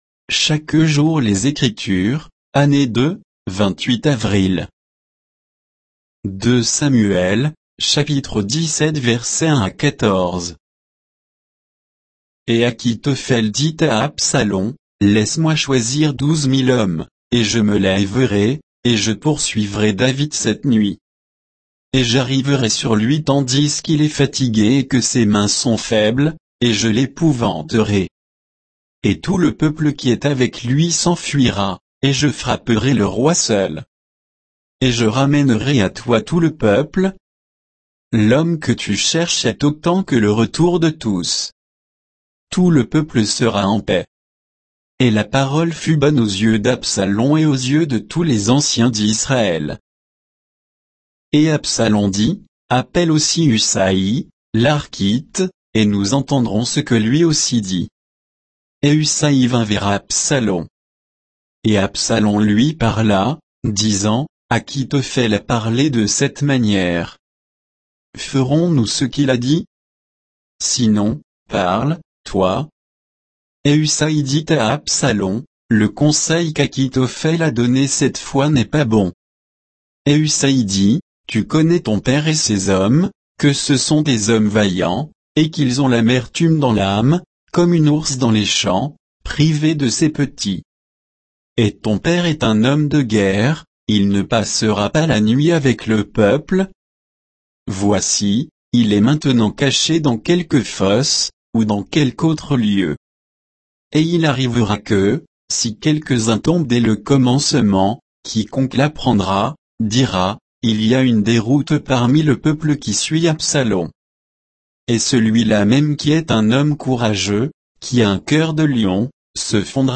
Méditation quoditienne de Chaque jour les Écritures sur 2 Samuel 17